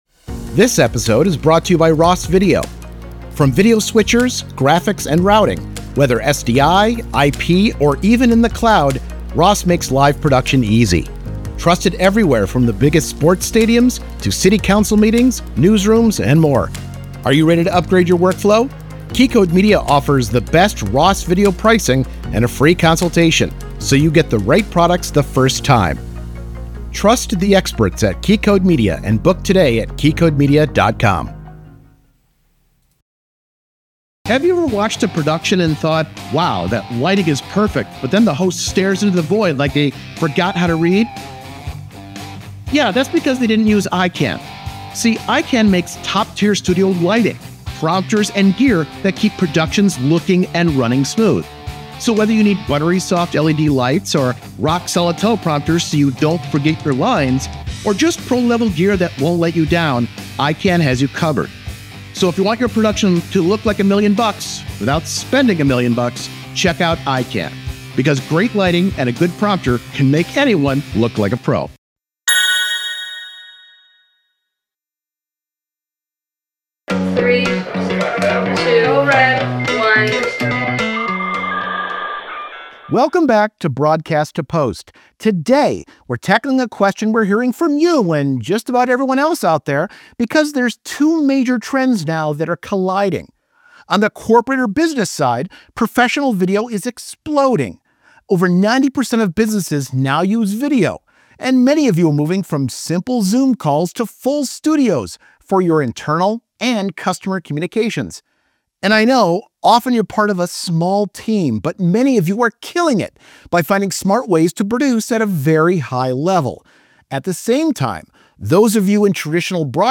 The conversation digs into what actually works when one person is running the show, and how t